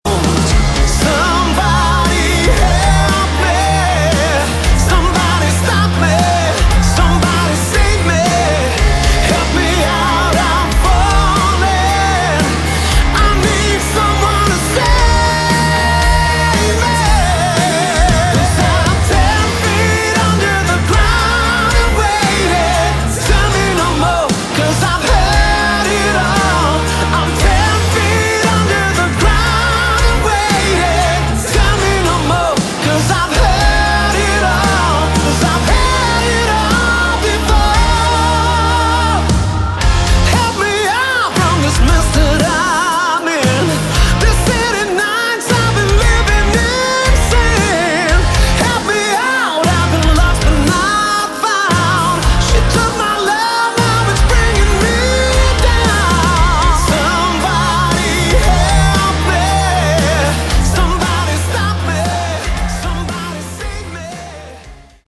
Category: Modern Hard Rock
lead vocals, backing vocals